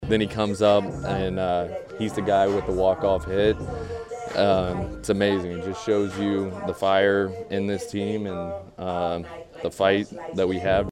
Kansas City Royals right fielder Drew Waters talking about catcher Freddy Fermin’s game winning hit in the eleventh.